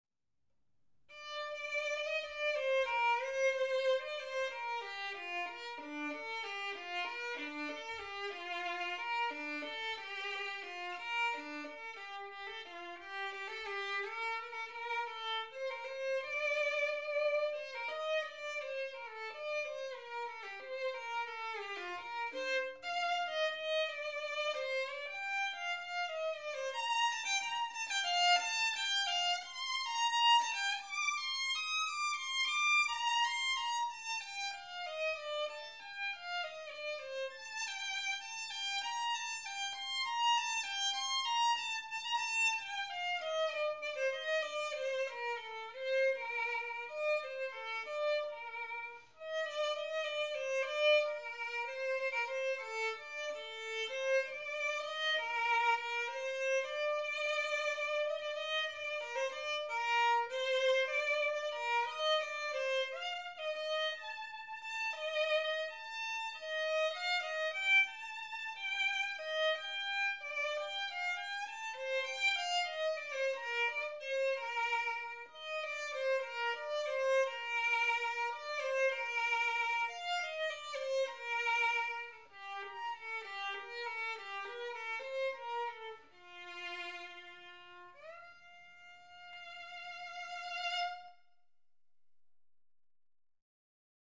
solo violin works
Workshop Recording